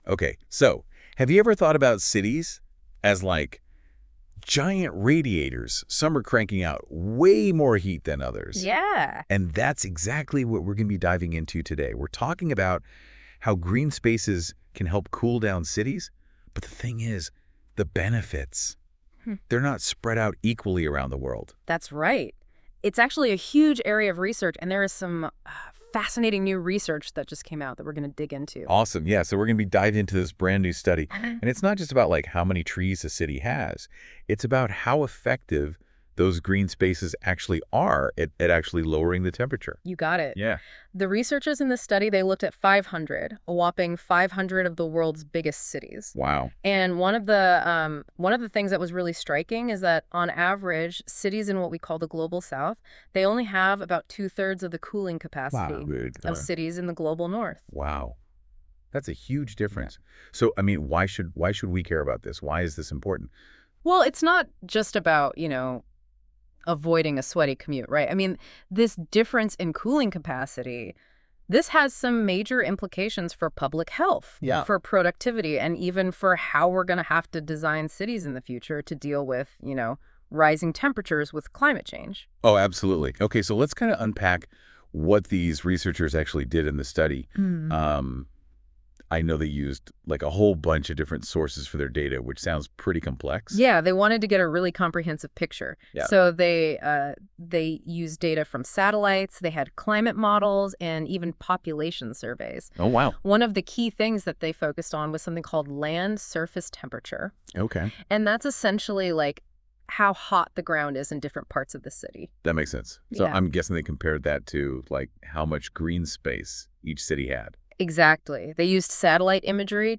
音频内容由AI自动生成，仅供参考。